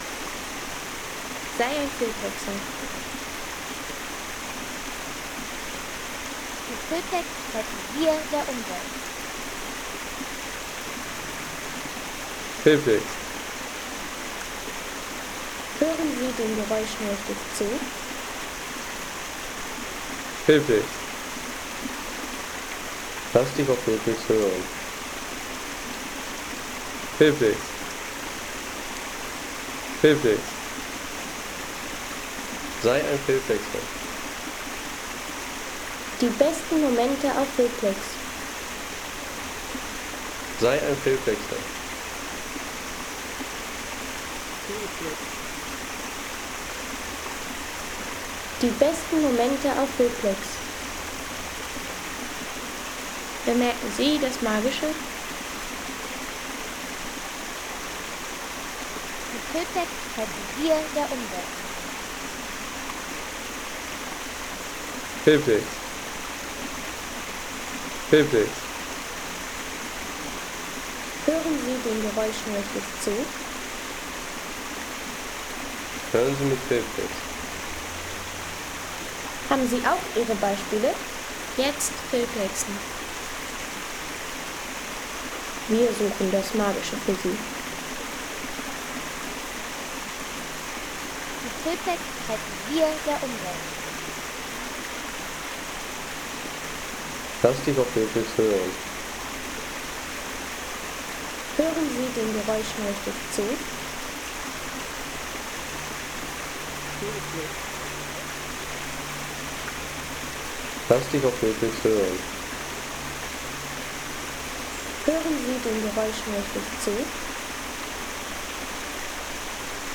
Peneus - Kaskaden Home Sounds Landschaft Wasserfälle Peneus - Kaskaden Seien Sie der Erste, der dieses Produkt bewertet Artikelnummer: 157 Kategorien: Landschaft - Wasserfälle Peneus - Kaskaden Lade Sound.... Peneus - Kaskaden im Bergpark Wilhelmshöhe – Vom Jussow-Tempel zum ... 3,50 € Inkl. 19% MwSt.